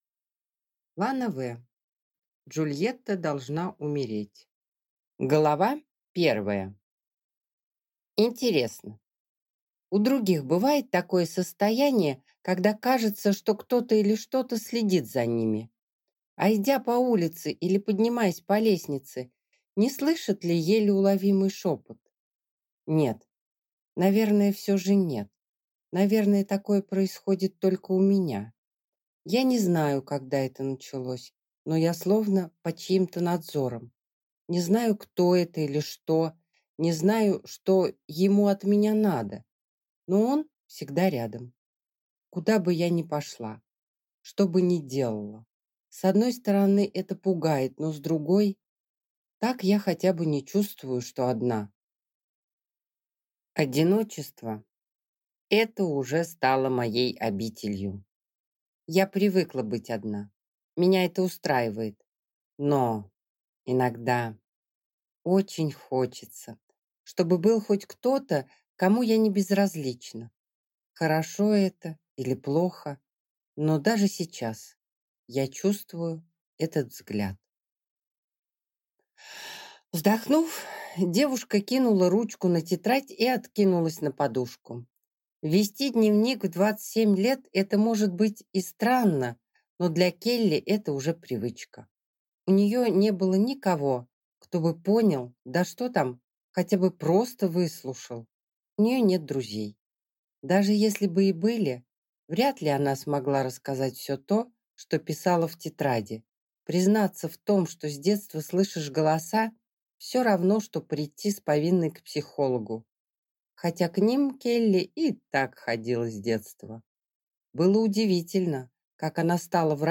Аудиокнига Джульетта должна умереть | Библиотека аудиокниг